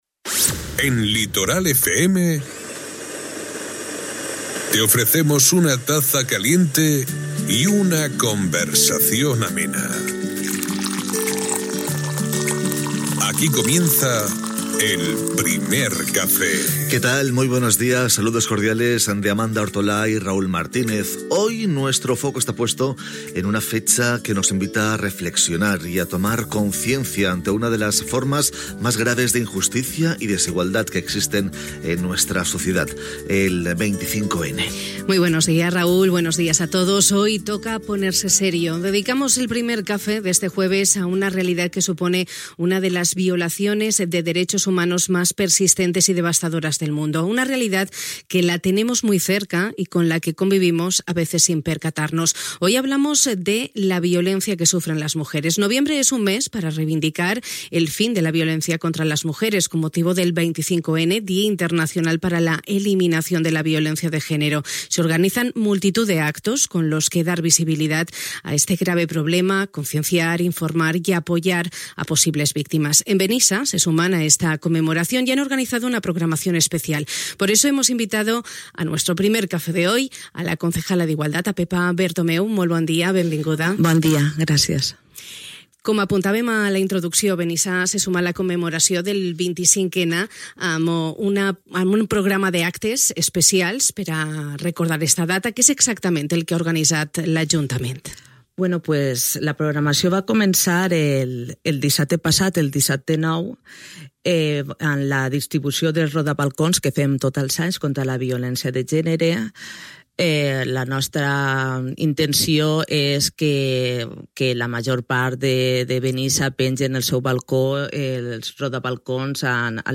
Una realitat que la tenim molt a prop i amb la qual convivim, de vegades sense adonar-nos. Hui hem parlat de la violència que pateixen les dones, a tan sols uns dies de la commemoració del 25N, Dia Internacional per a l’eliminació de la violència de gènere. I ho hem fet amb la regidora d’Igualtat de Benissa, Pepa Bertomeu.